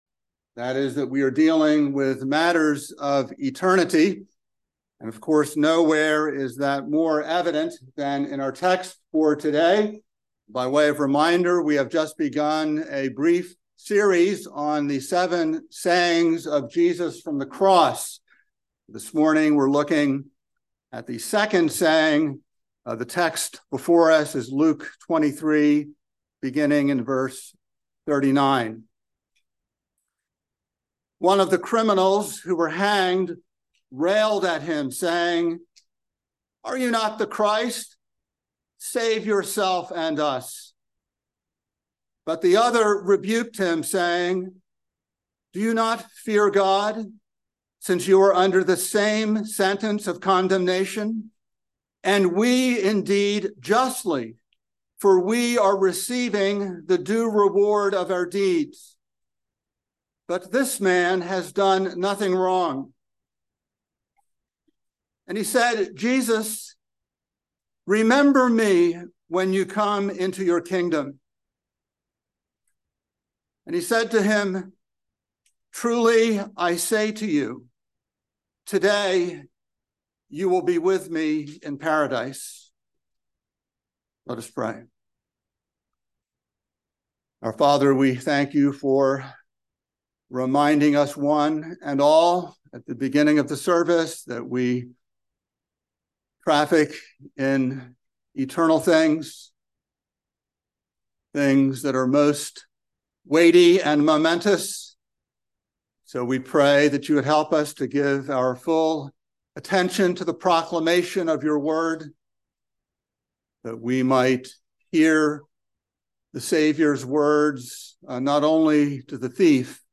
by Trinity Presbyterian Church | Nov 13, 2023 | Sermon